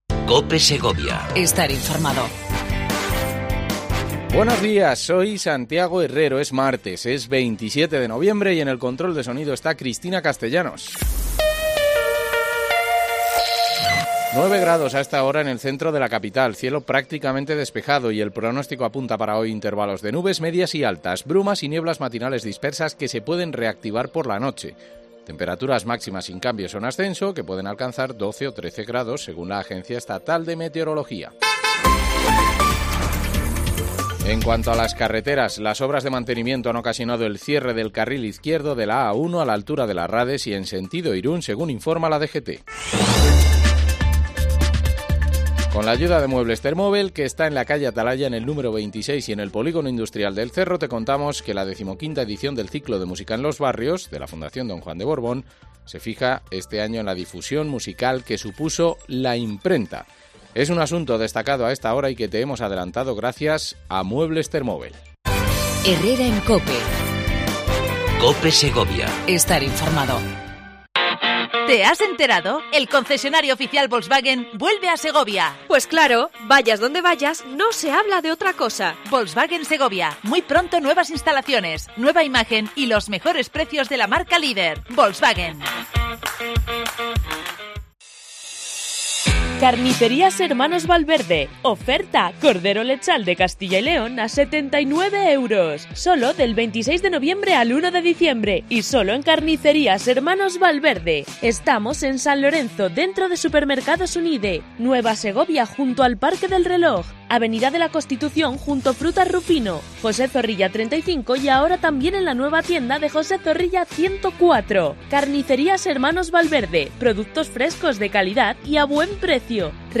AUDIO: Entrevista mensual a Lirio Martín, Subdelegada del Gobierno en Segovia